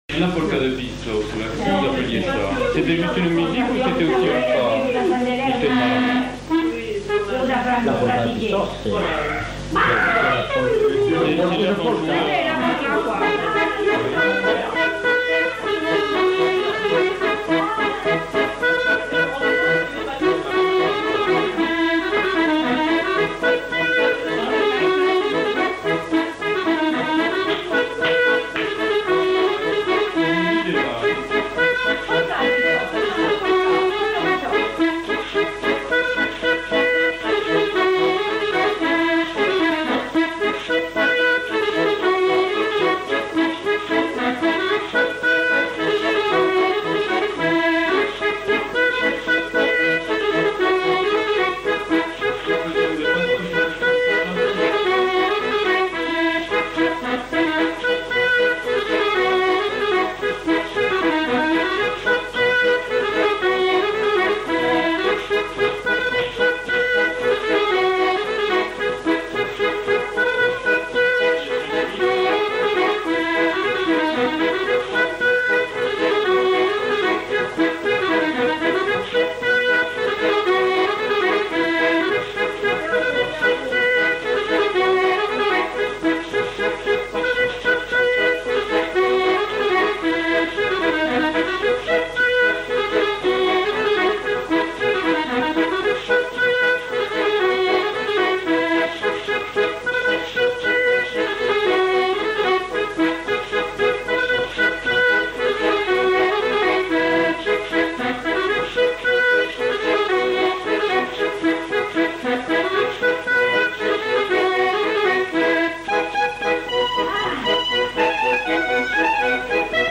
Répertoire de danses joué à l'accordéon diatonique
enquêtes sonores
Valse